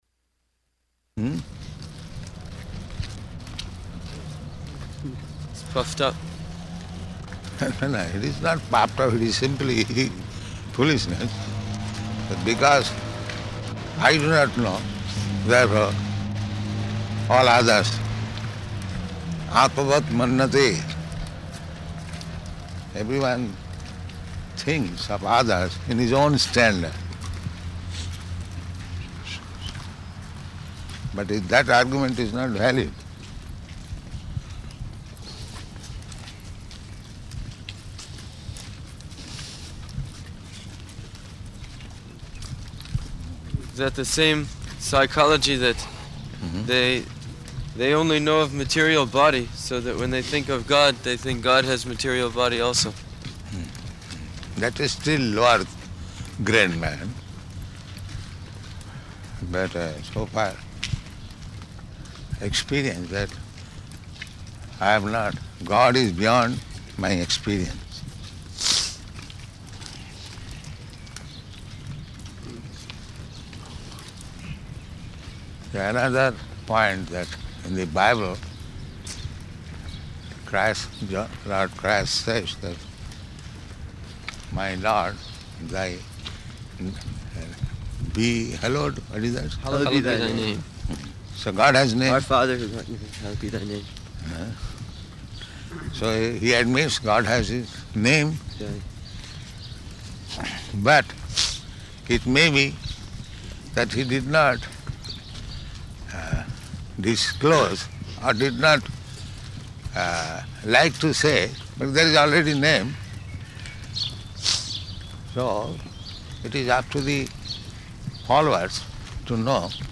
Morning Walk --:-- --:-- Type: Walk Dated: June 11th 1974 Location: Paris Audio file: 740611MW.PAR.mp3 Prabhupāda: Hmm?